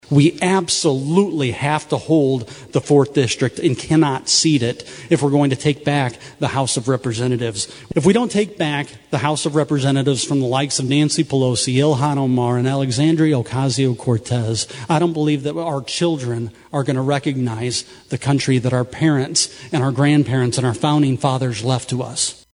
Congressman Steve King and three Republicans who’re planning to challenge the 4th District Representative in next year’s G-O-P primary spoke to a gathering of Christian conservatives Saturday night.